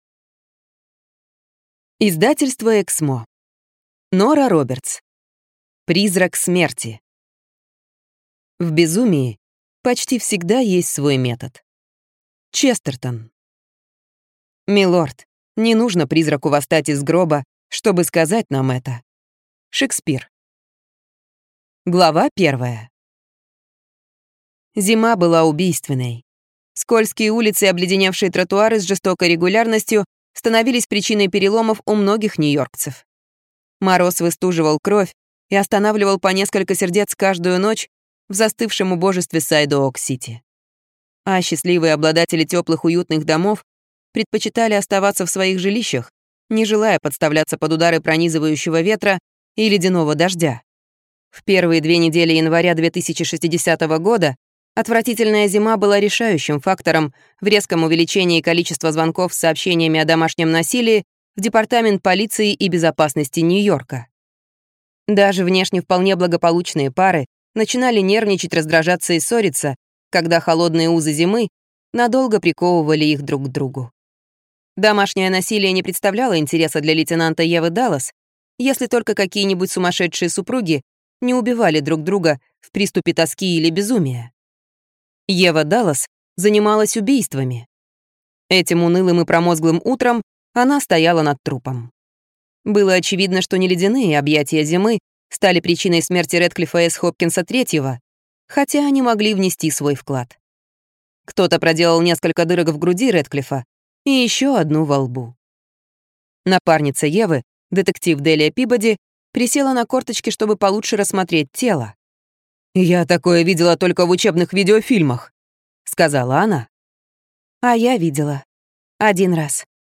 Аудиокнига Призрак смерти | Библиотека аудиокниг